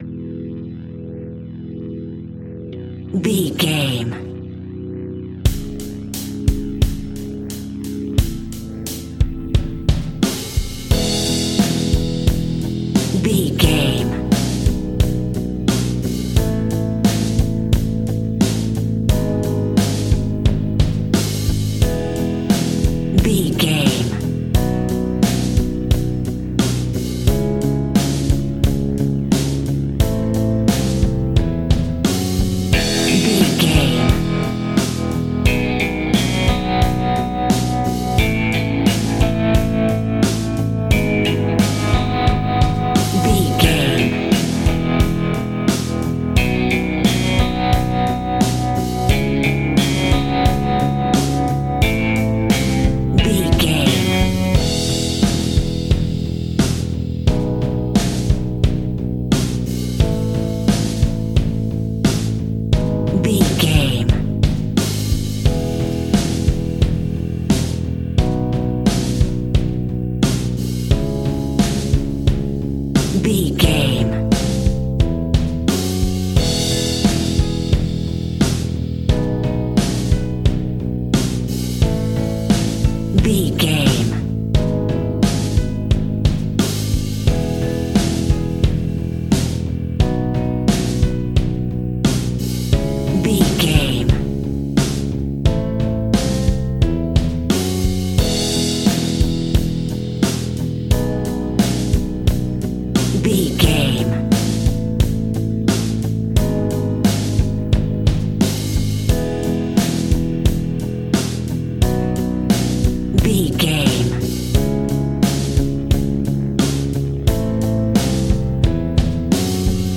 Rock Indie Ballad.
Ionian/Major
pop rock
drums
bass guitar
electric guitar
piano
hammond organ